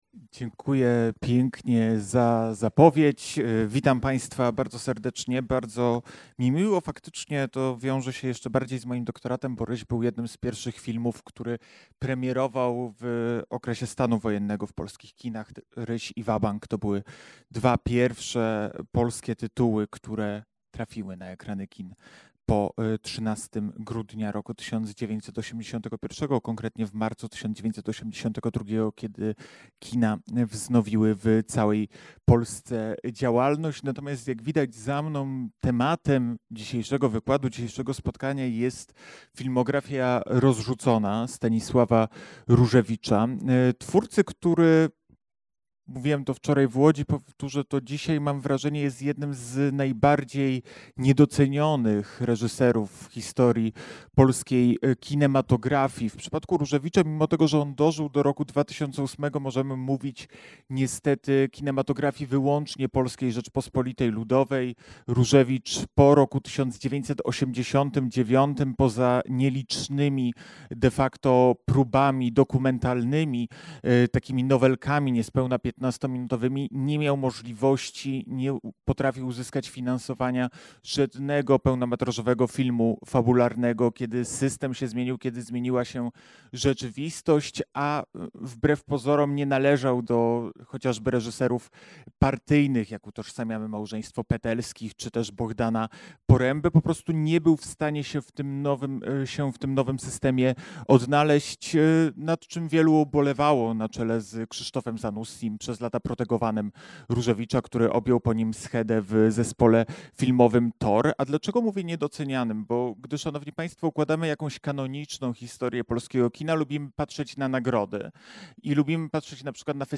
Akademia Kina Polskiego: wykłady audio - sem. I (2025/26) | Kino Nowe Horyzonty
Wykład z 15 października 2025